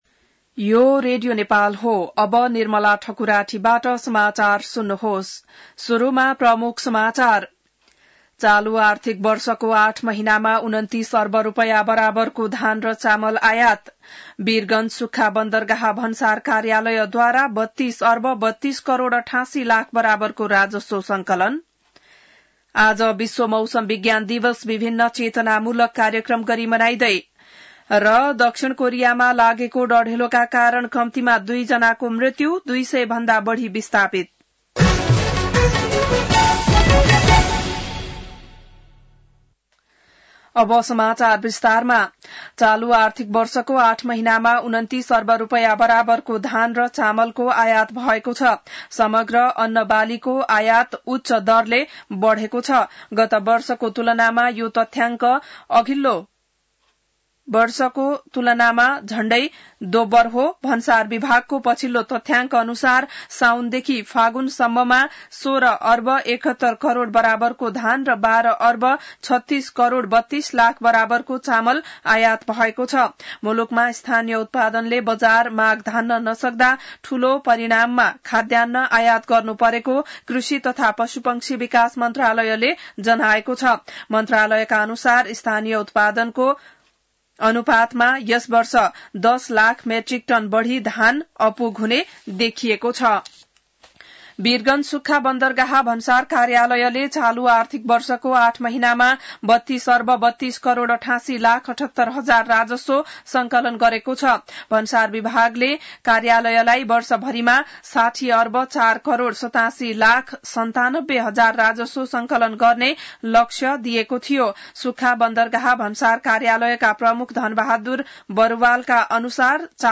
An online outlet of Nepal's national radio broadcaster
बिहान ९ बजेको नेपाली समाचार : १० चैत , २०८१